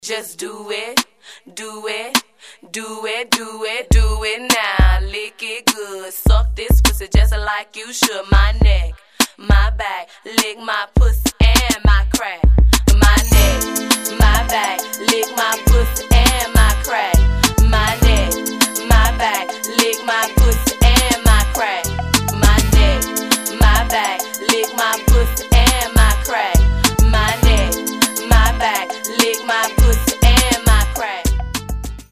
• Качество: 320, Stereo
Хип-хоп
RnB